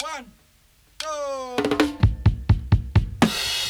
131-FILL-DRY.wav